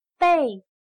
/Bèi/Equiparse con…,tener, estar provisto de…, prepararse, estar listo para….,tomar precauciones contra.